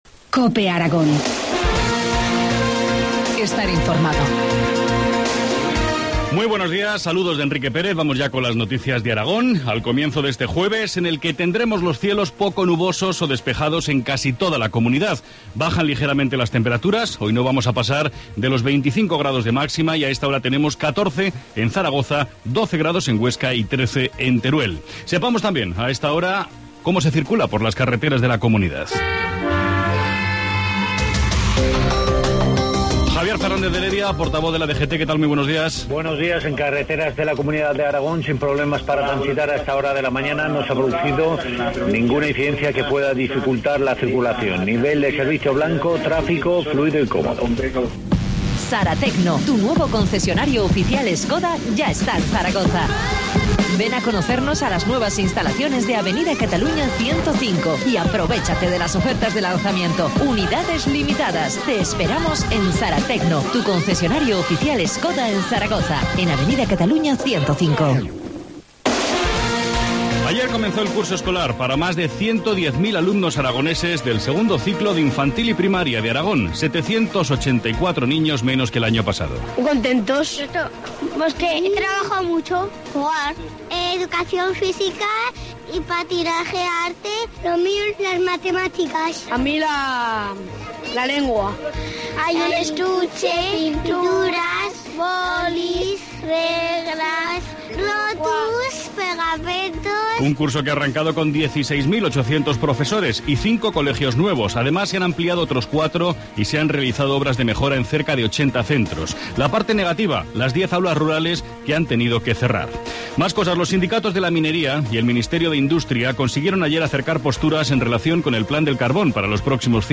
Informativo matinal, jueves 12 septiembre, 2013, 7,25 horas